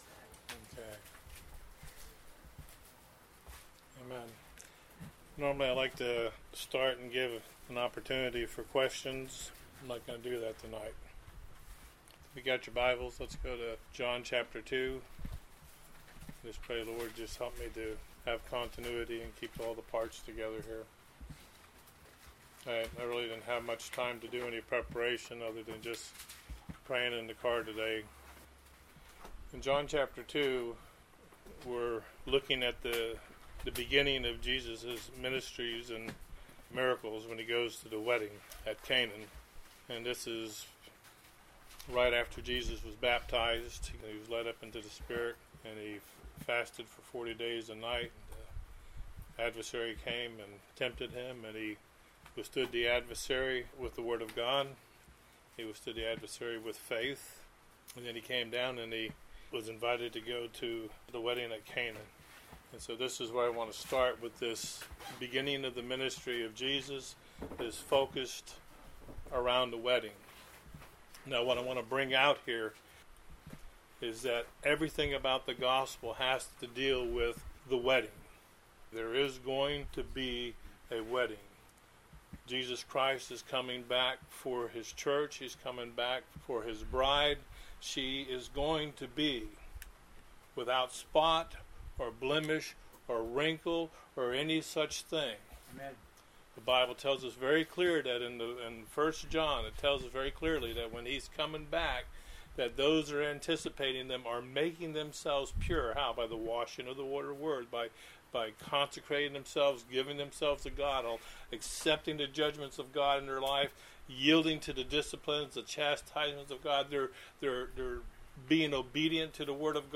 ` Its all about the wedding… (click link above to listen) The above is a message I shared at a men’s fellowship regarding the wedding at Cana of Galilee where Jesus performed His first miracle beginning His ministry.